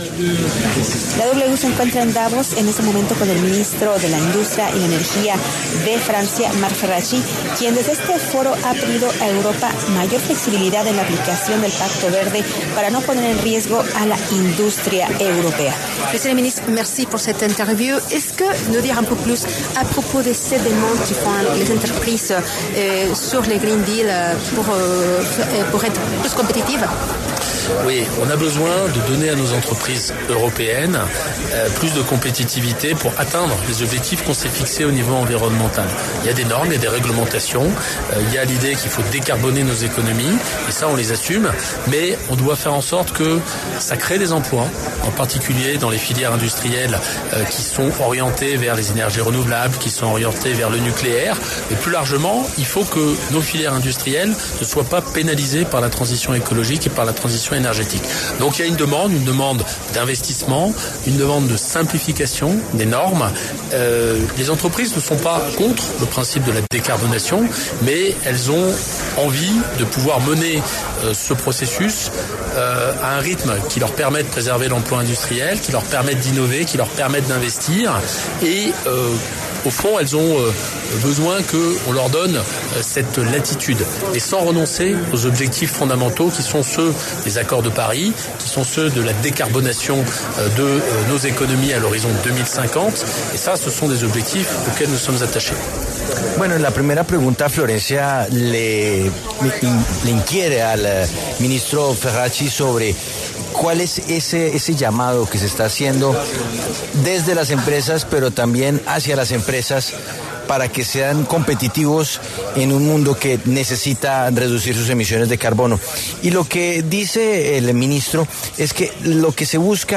Marc Ferracci, ministro de Industria y Energía en el gobierno del primer ministro François Bayrou, habló para los micrófonos de La W desde el Foro Económico Mundial de Davos, en Suiza.